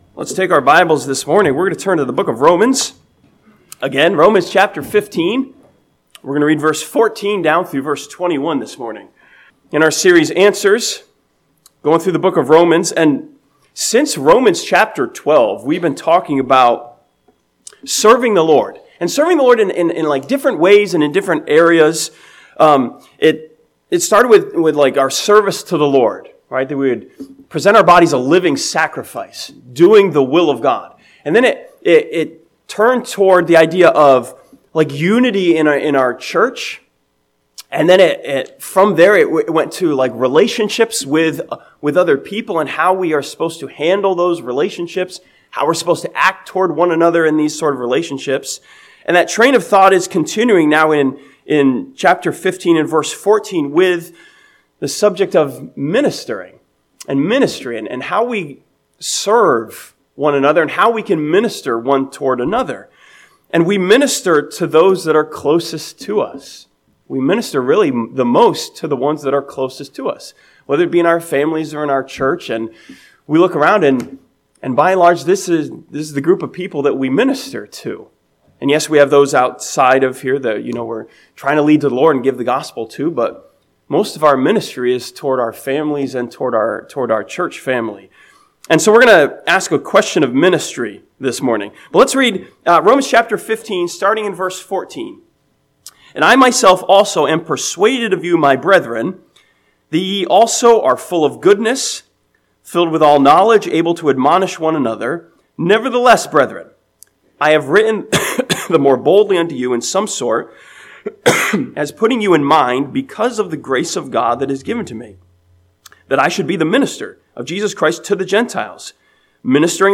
This sermon from Romans chapter 15 looks at Paul's ministry to the Gentiles and asks a question of ministry.